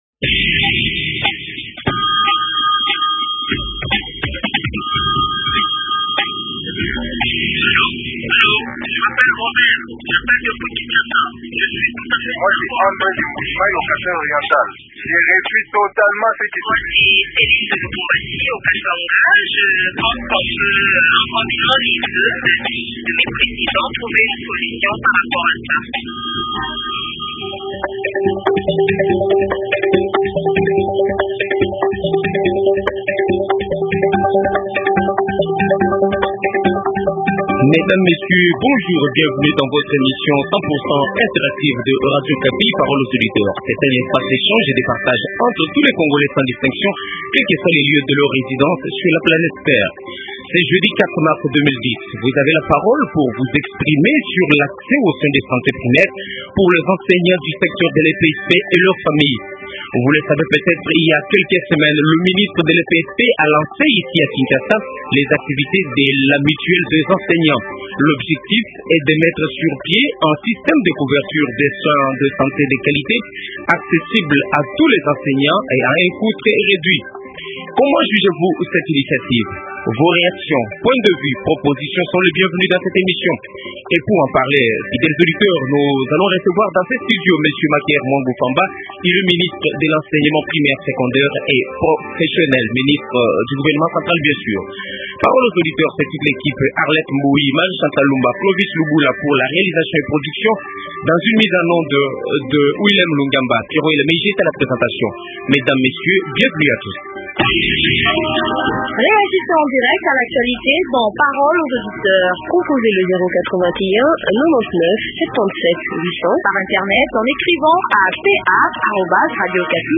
Invité: Maker Mwangu Mfamba, ministre national de l’Enseignement primaire, secondaire et professionnel.